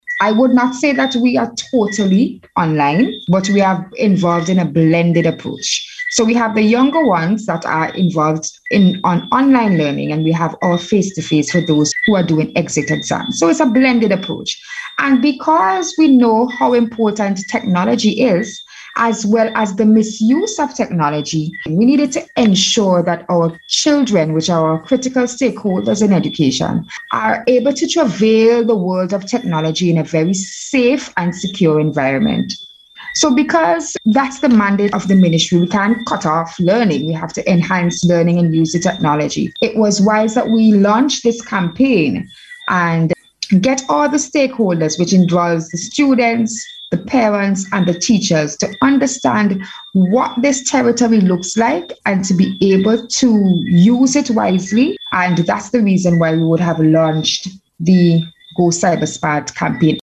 during the On the Beat programme aired on NBC Radio on Monday.